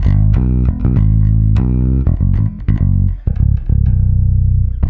-JP MEAN G#.wav